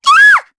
Luna-Vox_Damage_jp_03.wav